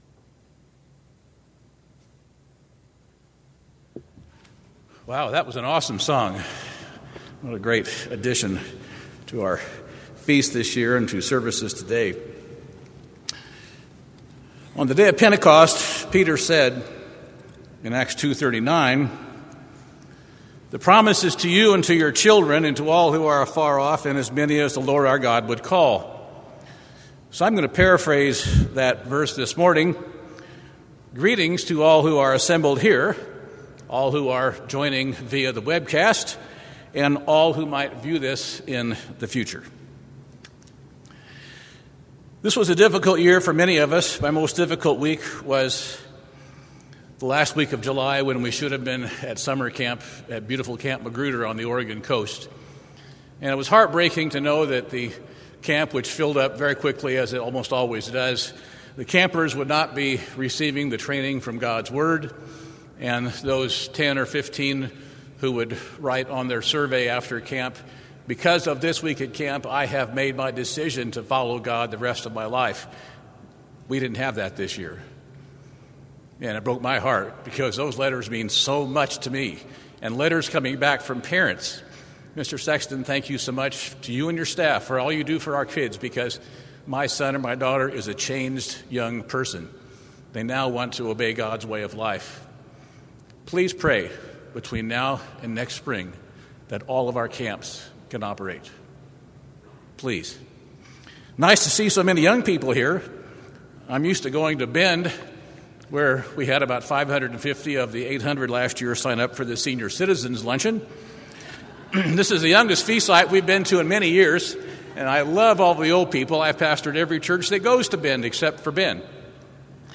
This sermon was given at the St. George, Utah 2020 Feast site.